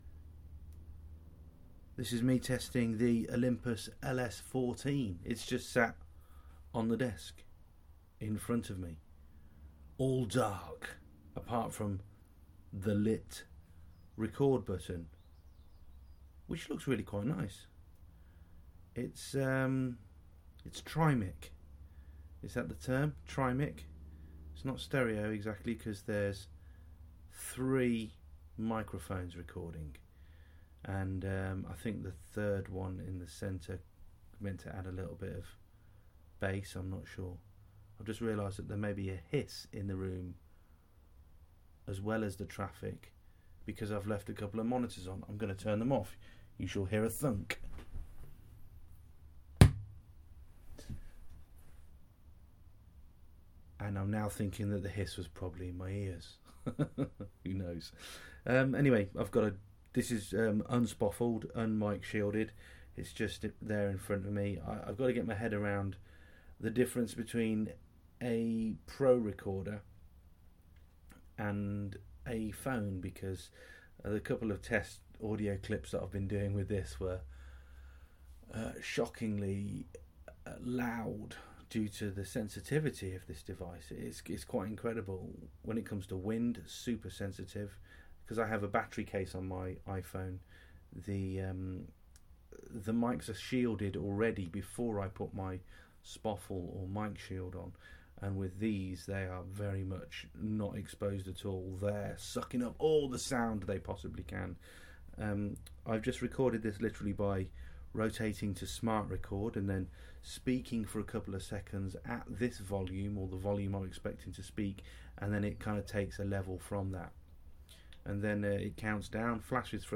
Test audio from the Olympus LS-14 Linear PCM Recorder
A WAV recorded using 'Smart Record' with the recorder stood 18 inches away on the desk in front of me.